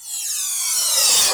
43_07_revcymbal.wav